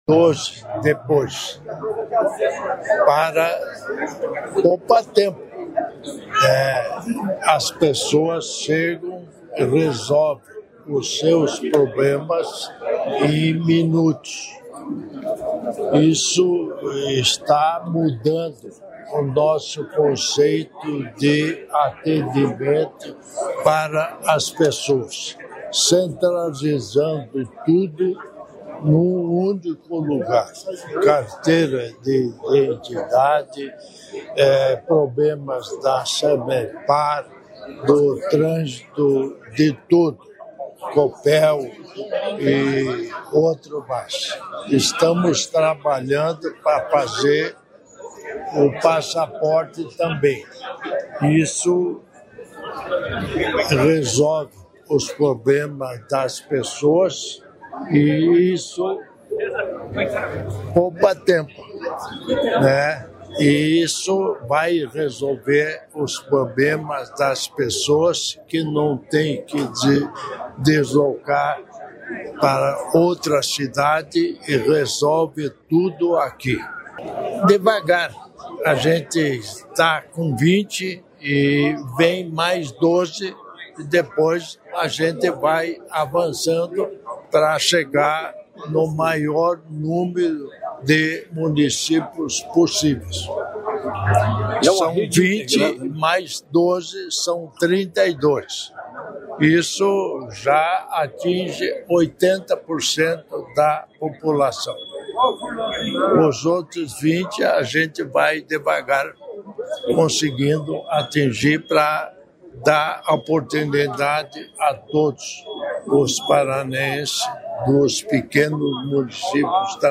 Sonora do vice-governador Darci Piana sobre o Poupatempo de Apucarana